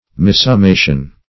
Missummation \Mis`sum*ma"tion\, n. Wrong summation.